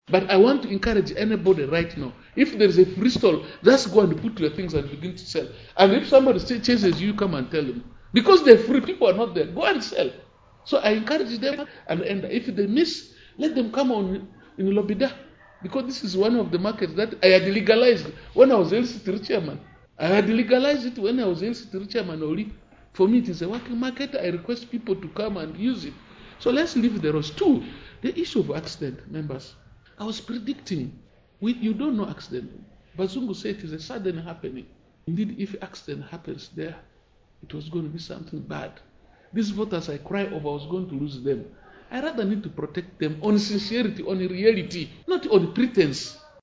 Hon Muzaid Khemis Speaking To Daily West Nile - Click On The Cue Audio To Listen